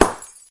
boom.ogg